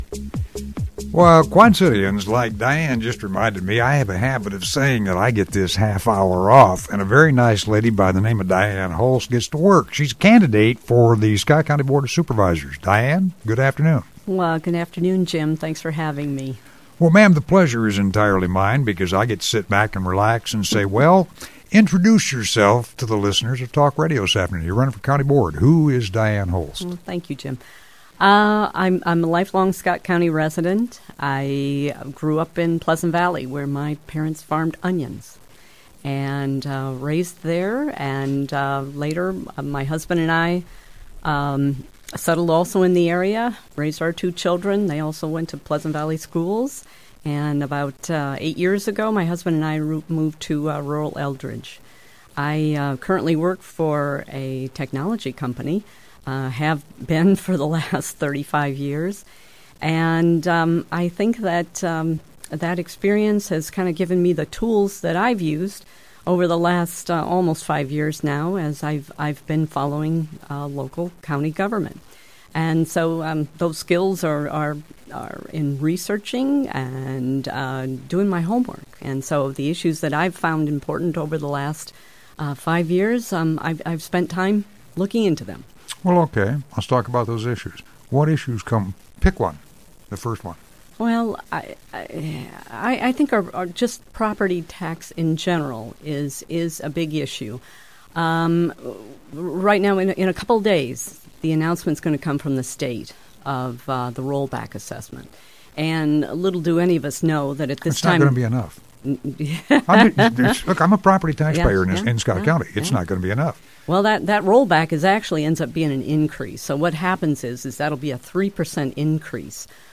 Interview
interview